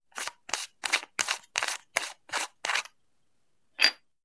Звук поворота защитной крышки оптического прибора